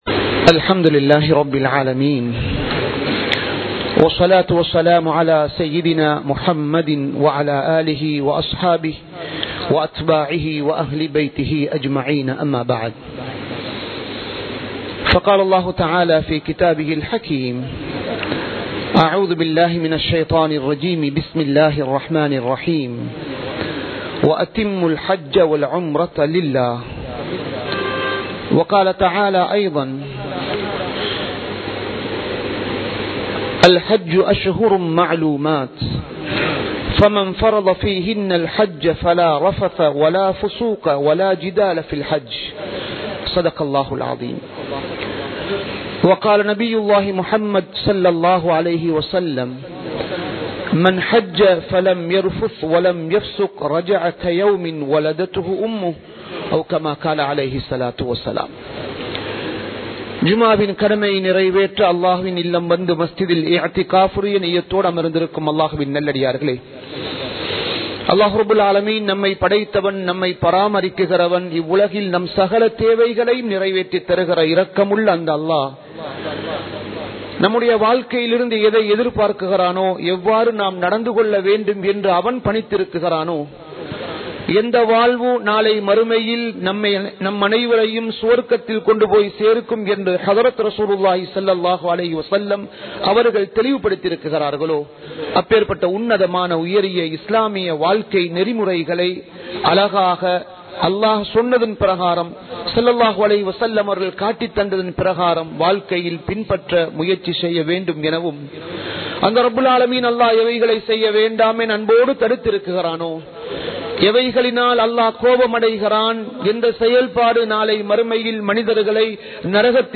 ஹஜ்ஜூடைய பாக்கியம் | Audio Bayans | All Ceylon Muslim Youth Community | Addalaichenai